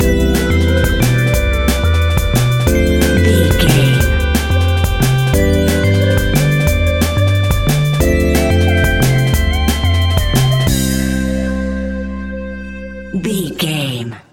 Ionian/Major
Fast
energetic
hypnotic
frantic
drum machine
synthesiser
sub bass
synth leads